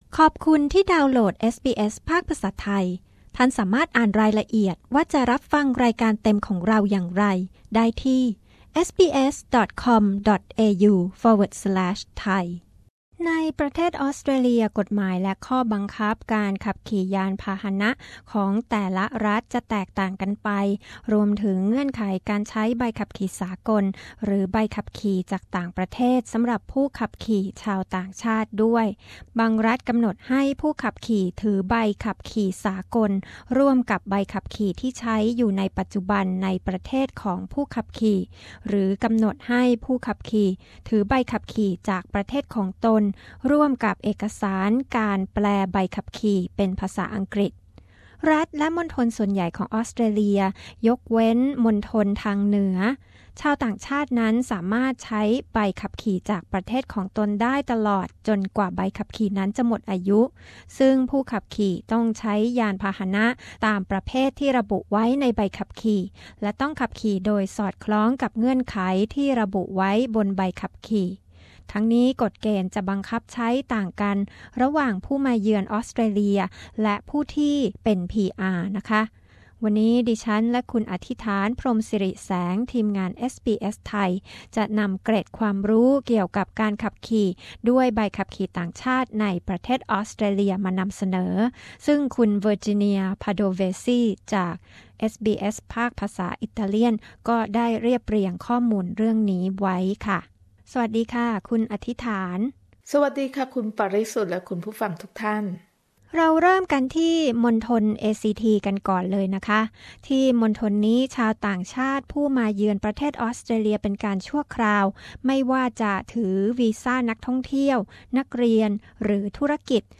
คุณขับรถในออสเตรเลียด้วยใบขับขี่ต่างประเทศได้หรือไม่ ใบขับขี่ของคุณต้องมีใบแปลเป็นภาษาอังกฤษหรือมีใบขับขี่สากลควบคู่ด้วยหรือไม่ และเมื่อไรที่คุณจำเป็นต้องสมัครขอใบขับขี่ของออสเตรเลีย ฟังรายละเอียดสำหรับทุกรัฐได้ในรายงานพิเศษจากเอสบีเอส